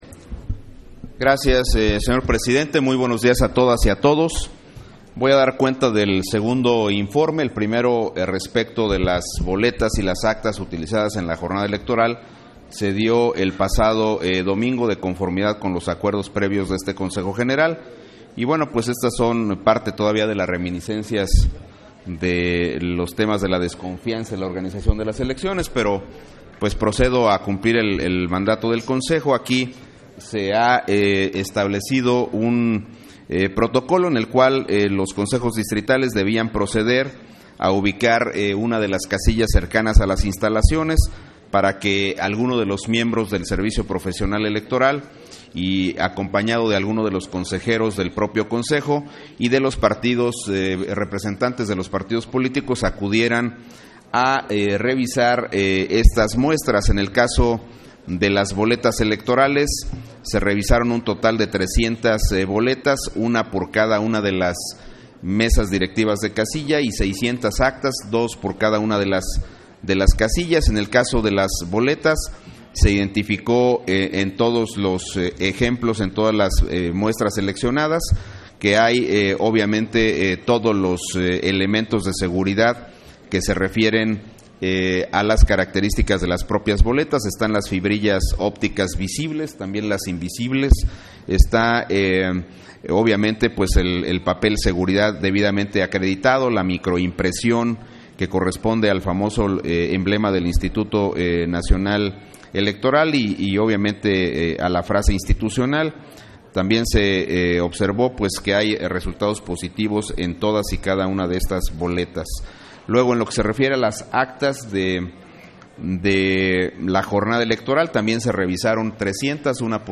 Palabras de Edmundo Jacobo sobre el informe de la instalación de los Consejos Locales y Distritales para la realización de Cómputos Distritales